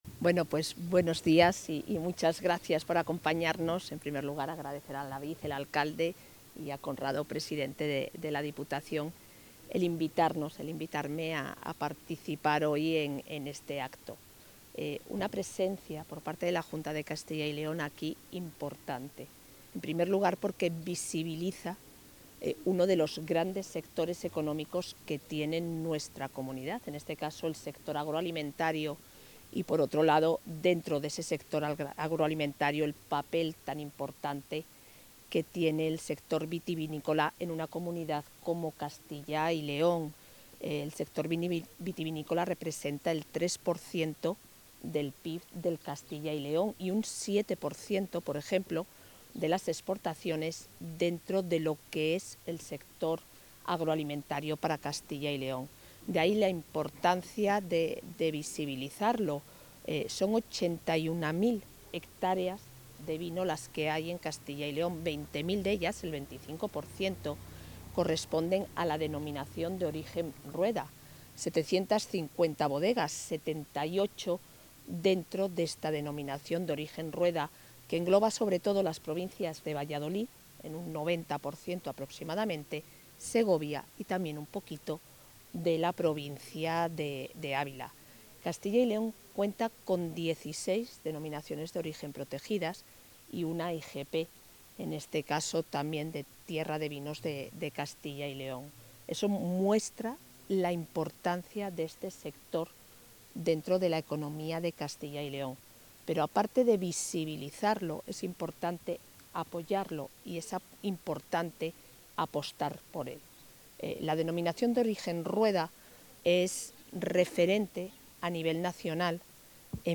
La vicepresidenta de la Junta de Castilla y León, Isabel Blanco, ha clausurado hoy el acto de presentación de la añada 2024 de...
Intervención de la vicepresidenta de la Junta.
La 'número dos' del Ejecutivo autonómico clausura la presentación de la añada 2024 de los 'Verdejos de Pozaldez', integrados en la Denominación de Origen Rueda, donde ha destacado el papel creciente de la mujer en un ámbito aún masculinizado.